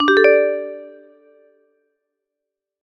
reply_send.ogg